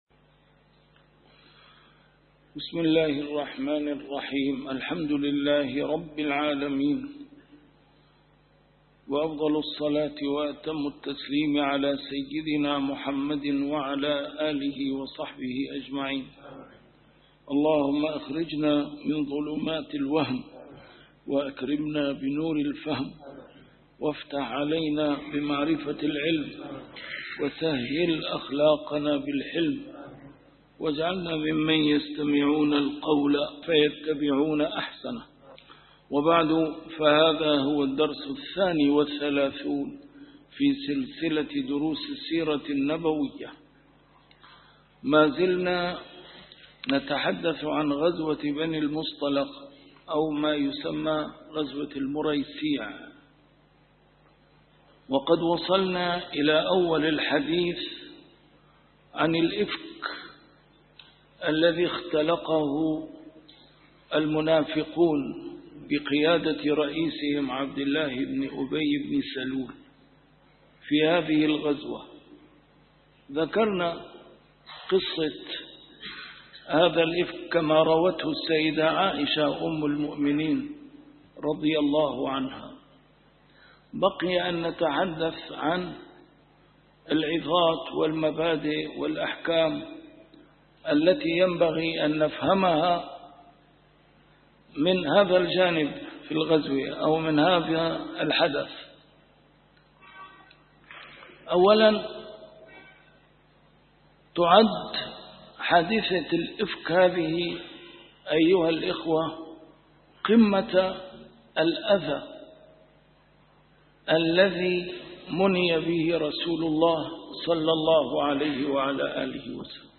A MARTYR SCHOLAR: IMAM MUHAMMAD SAEED RAMADAN AL-BOUTI - الدروس العلمية - فقه السيرة النبوية - فقه السيرة / الدرس الثاني والثلاثون : غزوة بني المصطلق والحديث عن حادثة الإفك